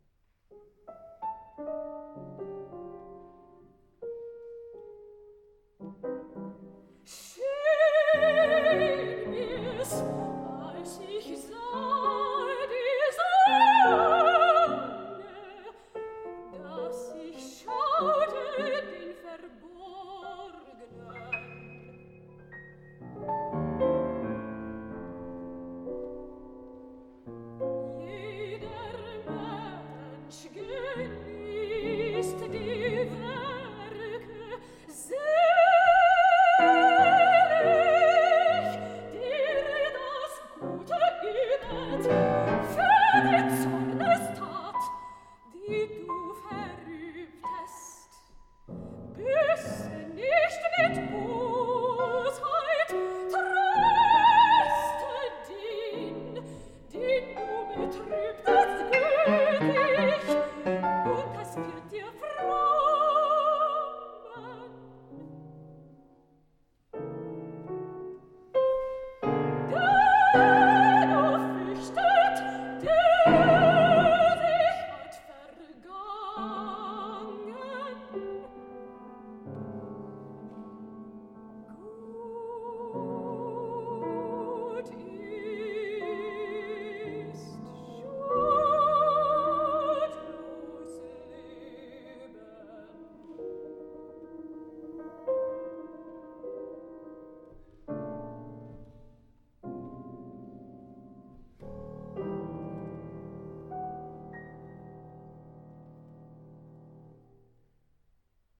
Art Song
soprano